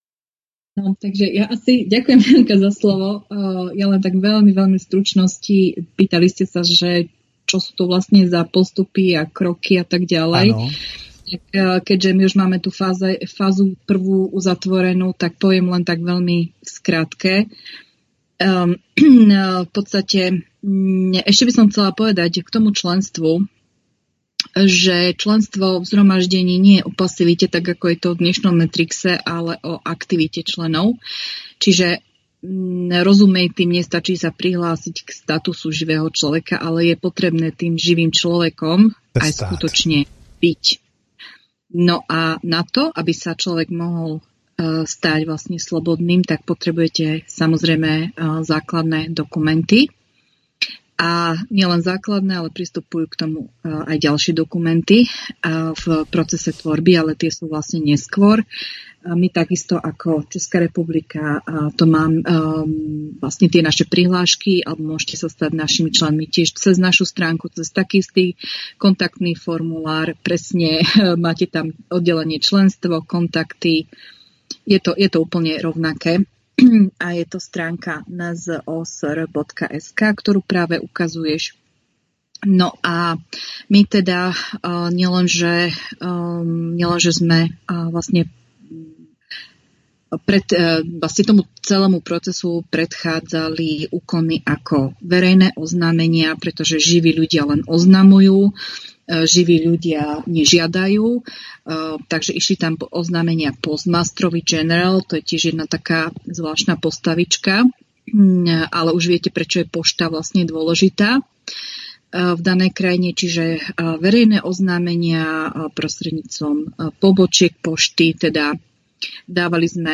Rozhovor na Svobodném vysílači, kde jsou vysvětleny základy pojmu "Živý člověk" a informace o Lidovém shromáždění živých lidí.